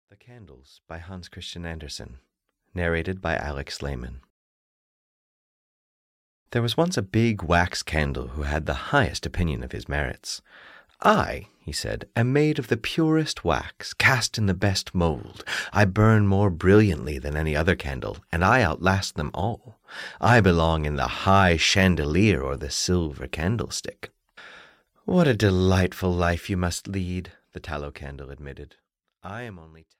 The Candles (EN) audiokniha
Ukázka z knihy